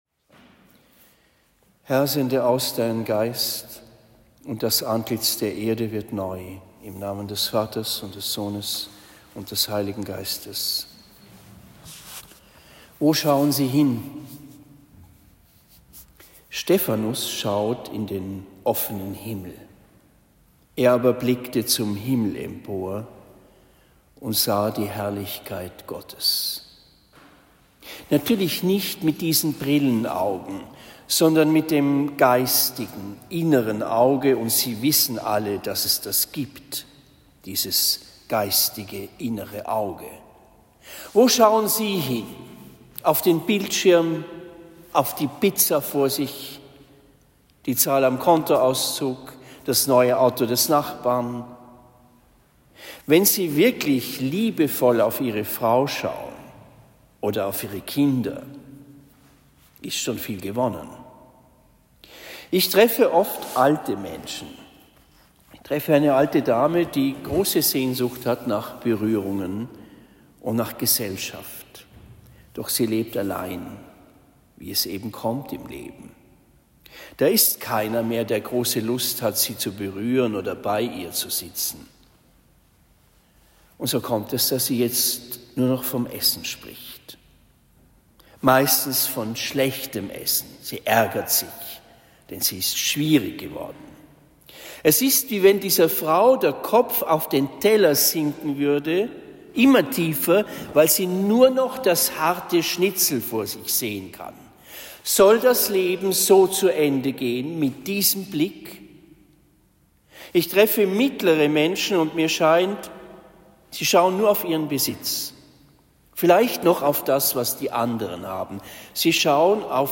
Stephanitag 2023 Predigt in Marienbrunn St.-Barbara und in Zimmern St.-Michael am 26. Dezember 2023